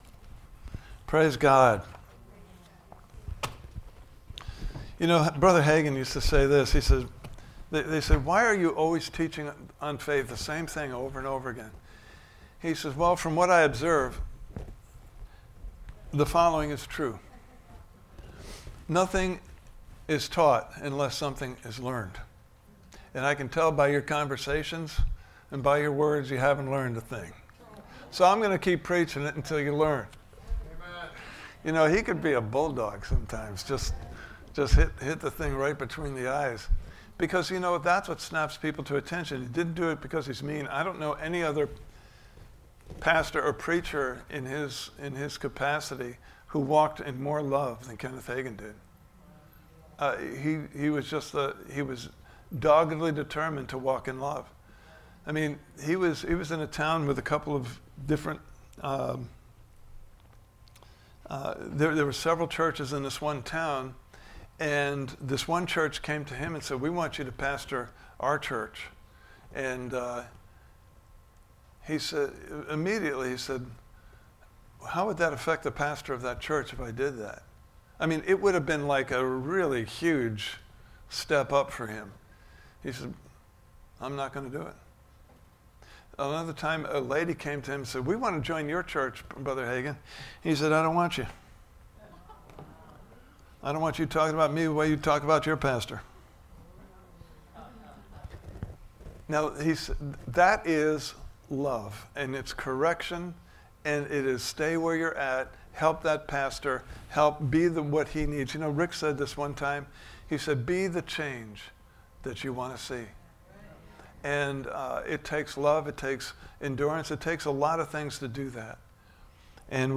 Service Type: Sunday Morning Service « Part 4: What About Job?